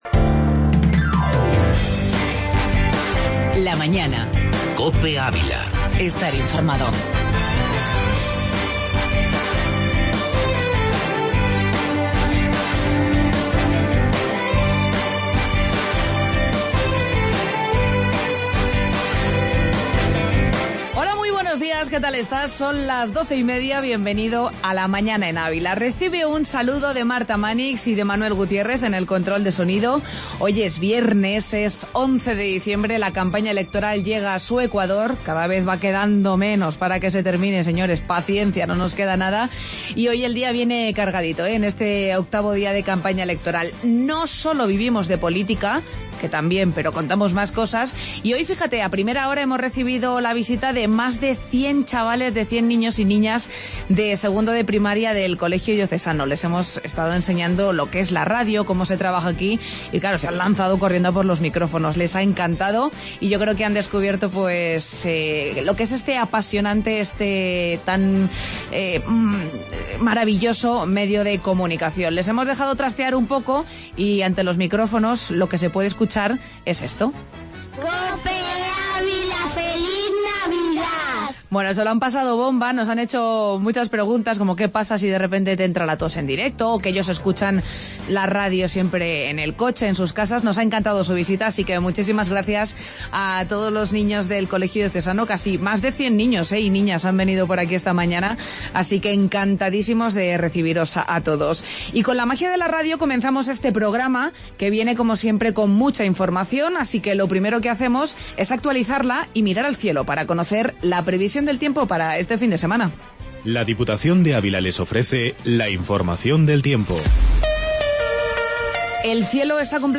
AUDIO: Entrevista campus divernávila y el folio en Blanco